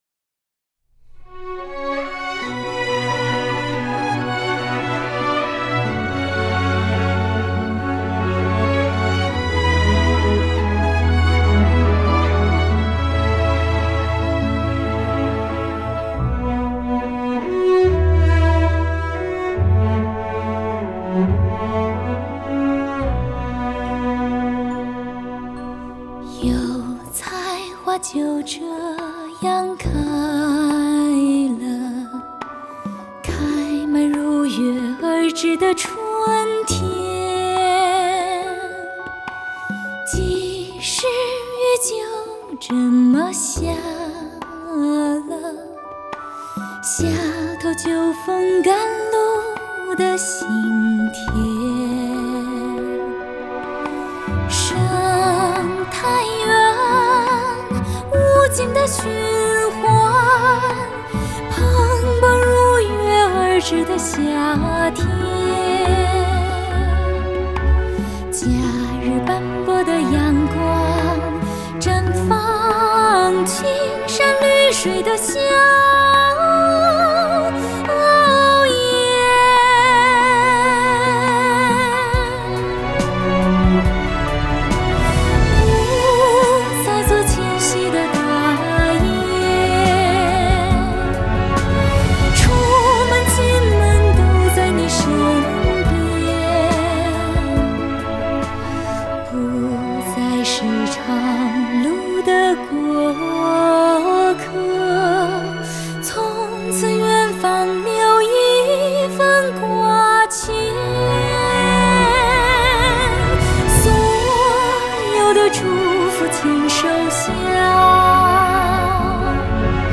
歌曲把新农村建设融入时代的进步；曲风优美、流畅、温暖，是一首赖人寻味可以反复聆听的音乐作品。